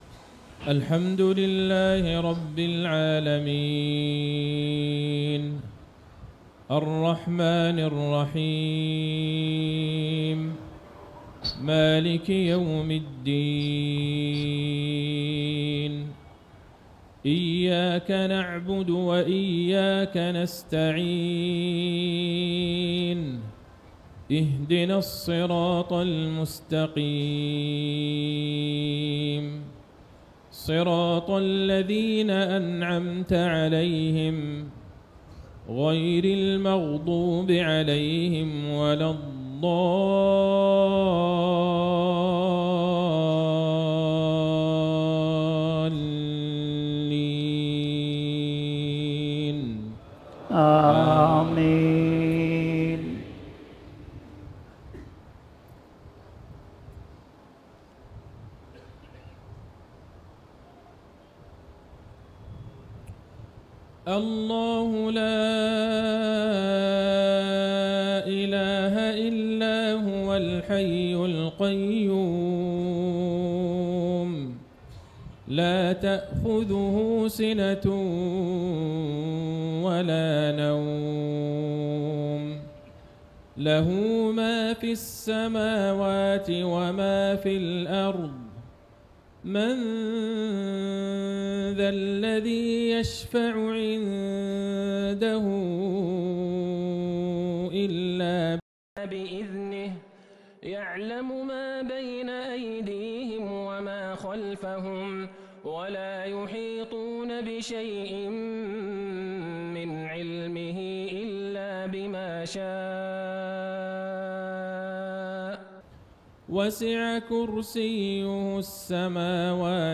صلاة المغرب ٨-٥-١٤٤٦هـ في مؤتمر جمعية أهل الحديث في عاصمة الهند نيودلهي